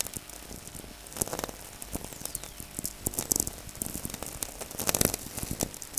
Cumiana, NW Italy